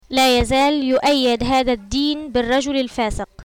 Listen to this read by a native speaker of Arabic.